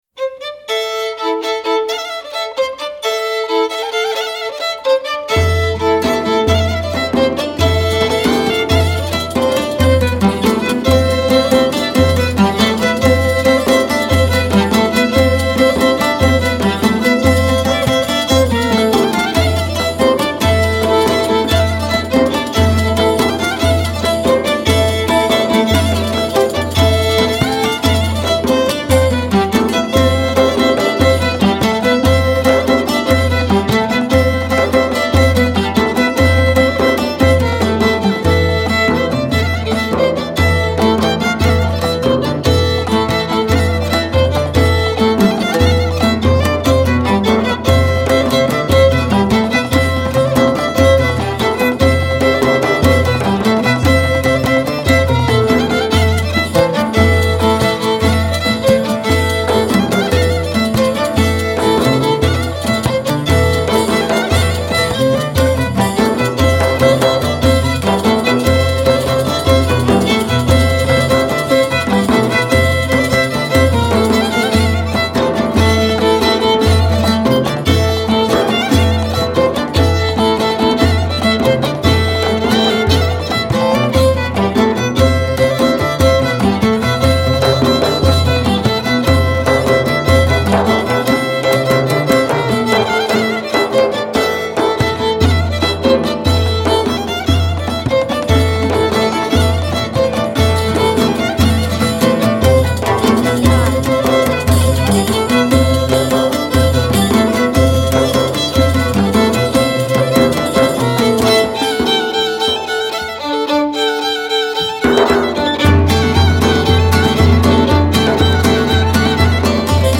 Union Hall Coffeehouse
She announced from stage that these are dance pieces from the Black Sea.
pontic lyra
Riq
oud